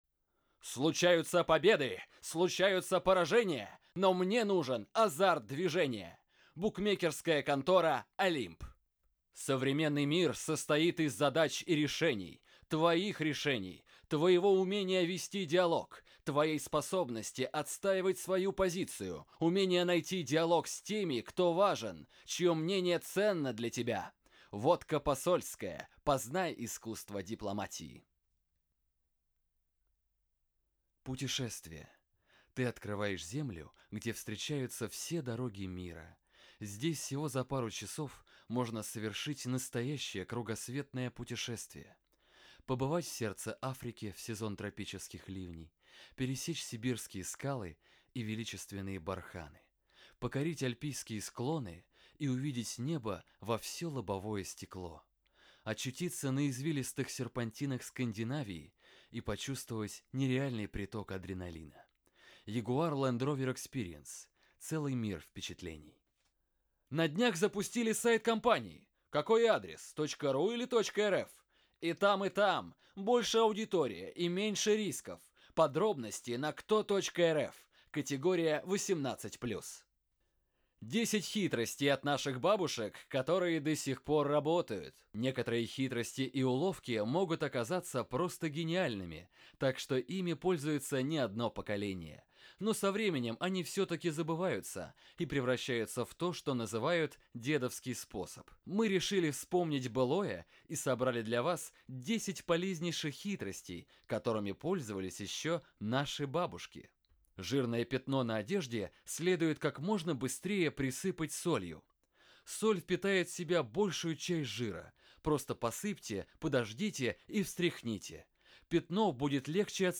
Скачать демо диктора
:))))) Универсальный диктор - диапазон озвучки от ясельной девчушки до бабушки-старушки:)). 20 лет непрерывной работы - рекламная и прочая озвучка, пародии, ведущая программ Радио и ТВ.
Отличая дикция.